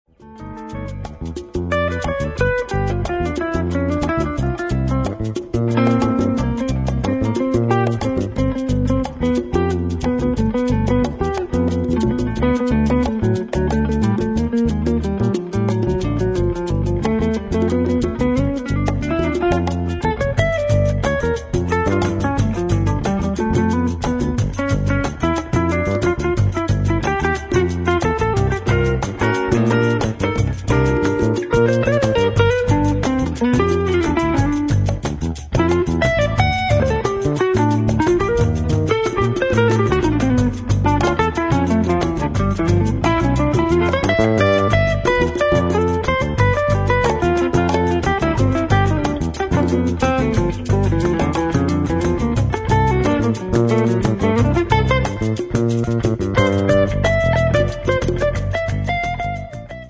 Basso e Contrabbasso